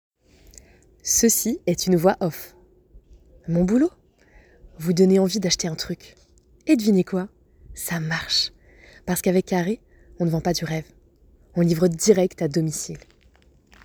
voix off 2